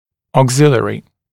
[ɔːg’zɪlɪərɪ][о:г’зилиэри]вспомогательный, дополнительный, добавочный; запасной